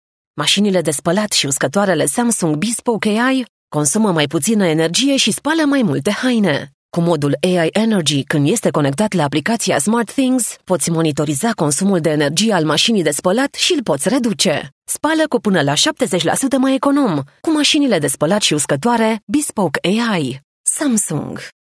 Rumänisch
Natürlich, Zugänglich, Zuverlässig, Kommerziell, Corporate
Kommerziell